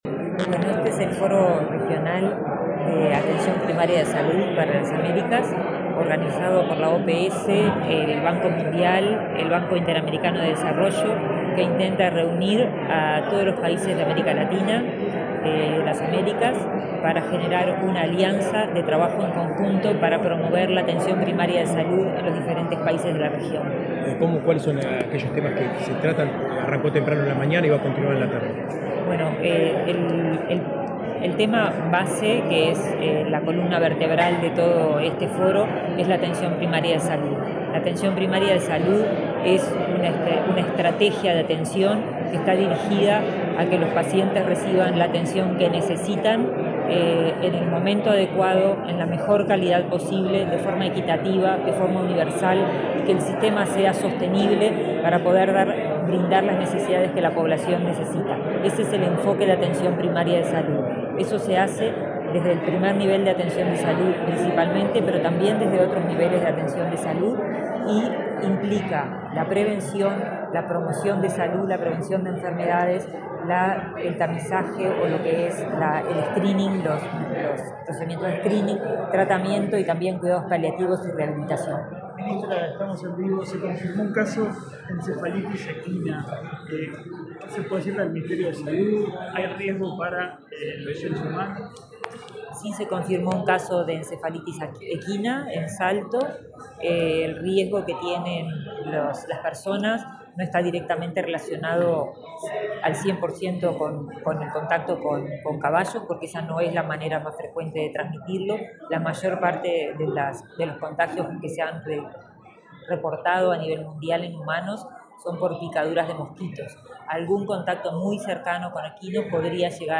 Declaraciones de la ministra de Salud Pública, Karina Rando
Luego dialogó con la prensa.